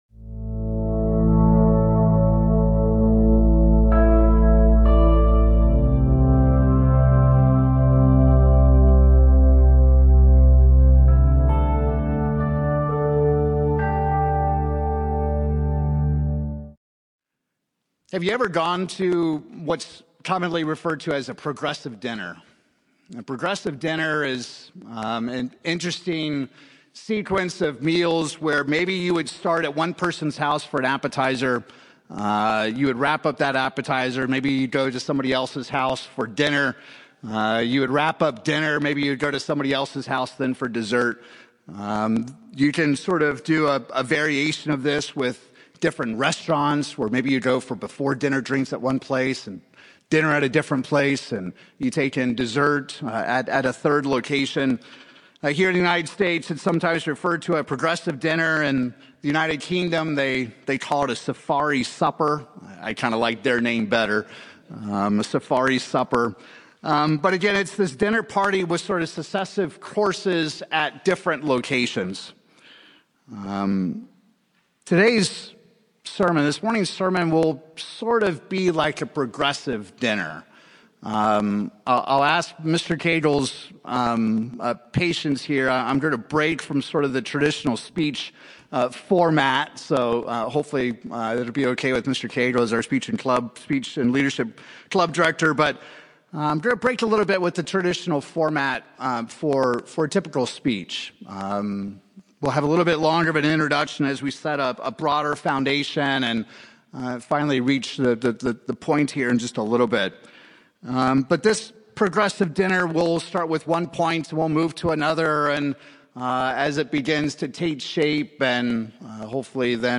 But being satisfied and content with the circumstances that God has blessed us with is said to be next to godliness. In this sermon, we will journey through several key scriptures that help describe how we can be content in a world filled with discontent.
Given in Tulsa, OK Oklahoma City, OK